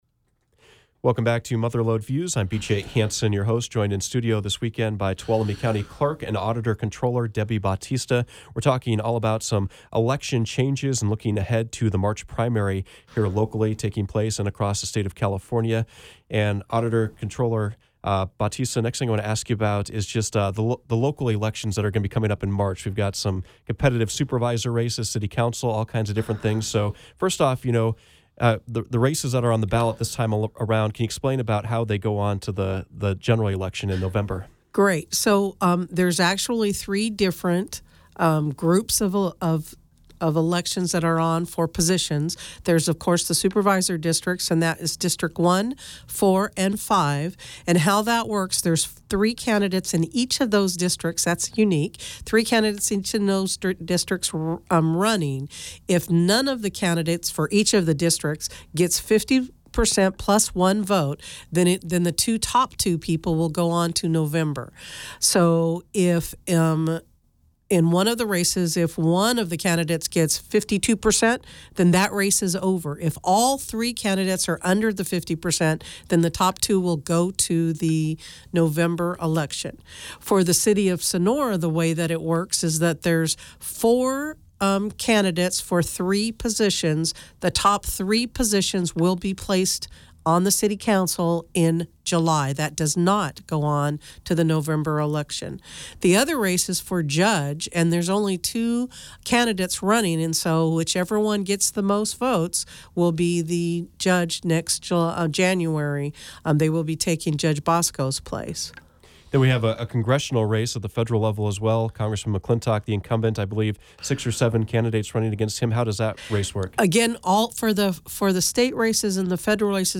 The guest was Tuolumne County Clerk and Auditor Controller Debi Bautista. All Tuolumne County voters will receive a ballot in the mail for the upcoming primary election and traditional polling sites are going away in favor of new Voting Centers.